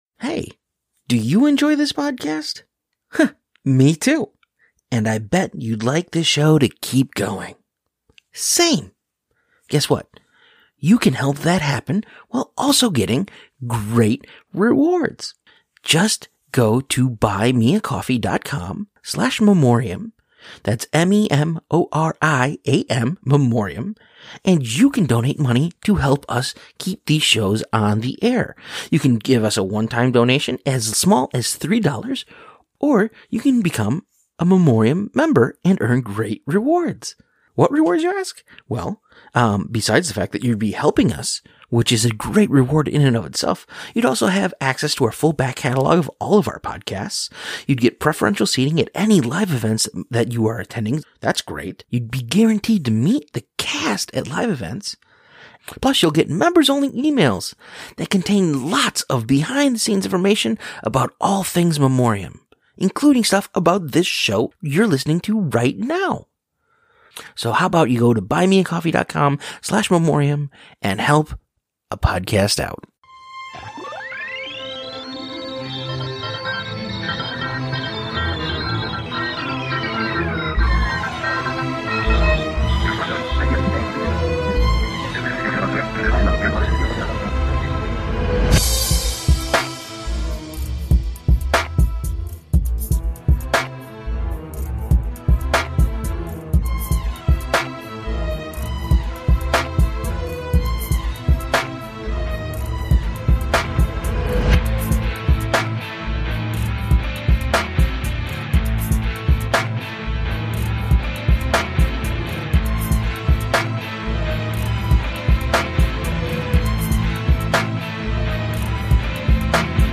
So we thought we would pull back the curtain a little, and share the audio from the panel. Be ready for some behind the scenes insight.